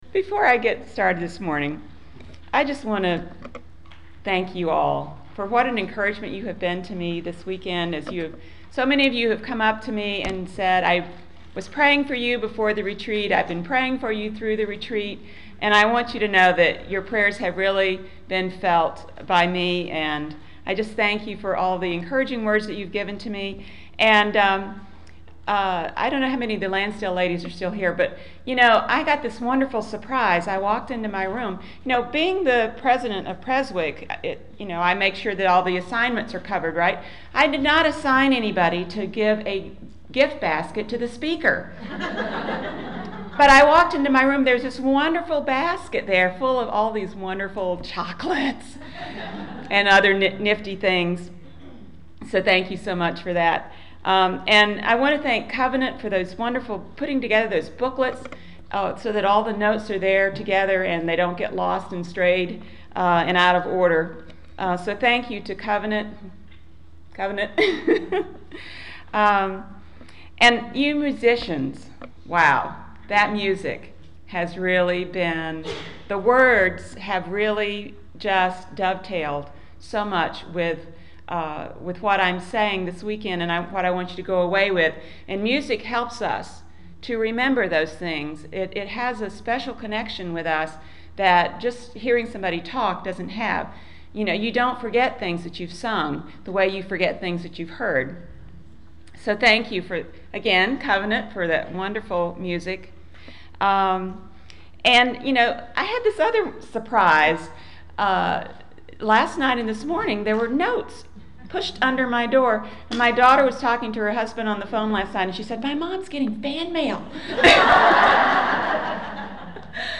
At the end of April I spoke at our Presbytery women's spring retreat on finding our purpose.